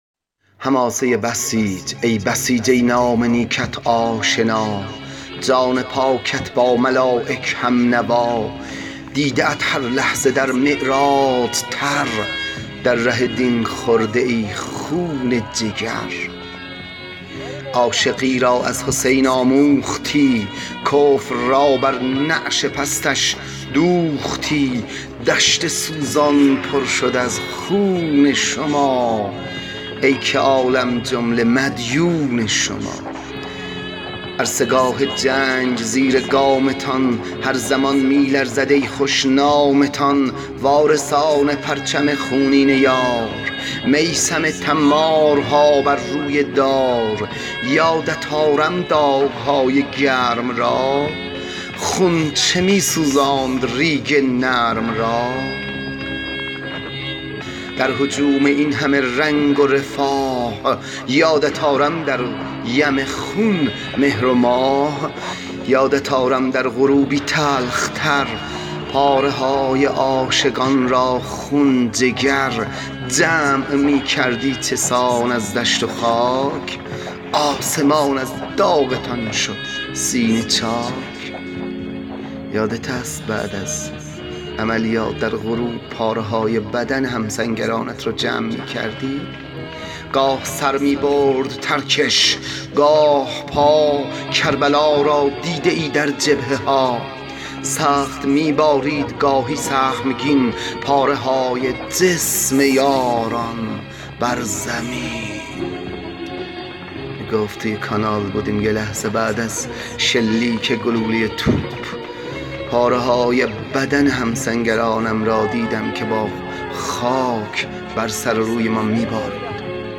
🔮 پادکست حماسه شهدا و بسیج ✍ با اشعار و دکلمه